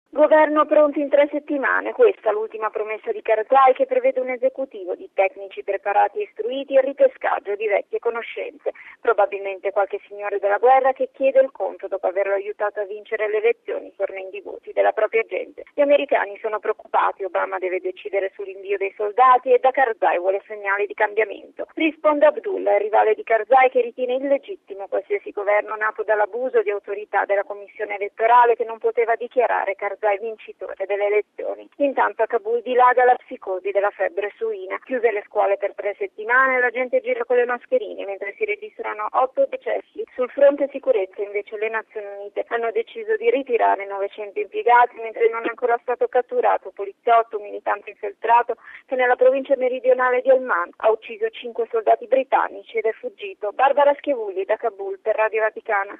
Da Kabul